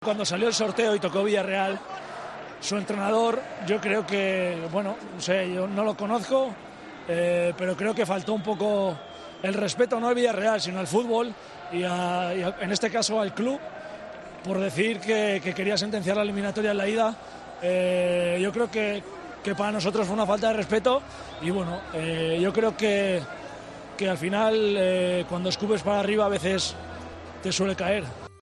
Dani Parejo aprovechó los micrófonos de Movistar para mandarle un mensaje a Nagelsmann tras la clasificación del Villarreal para semifinales de la Champions.